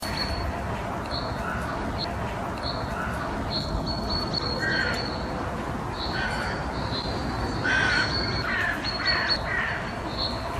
Waldgeräusch 8: Waldvogel und Krähe / forest sound 8: forest bird and crow